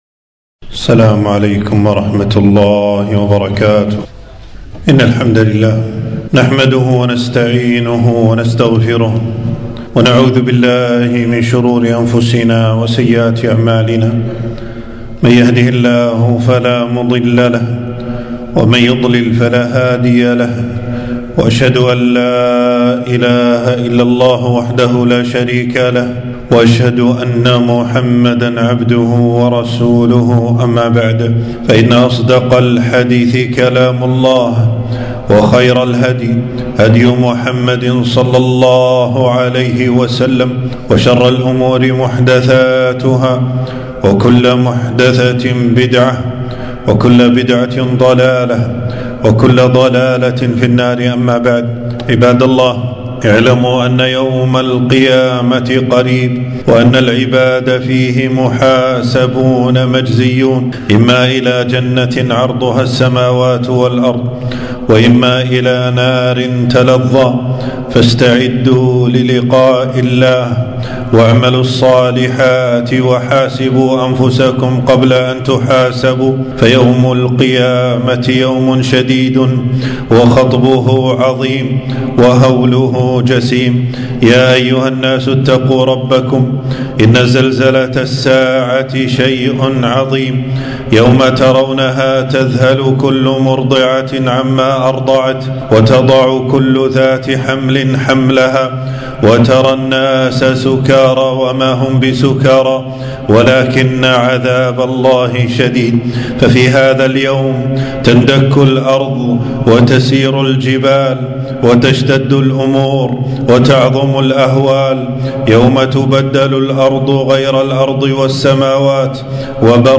خطبة - من أحوال وأهوال يوم القيامة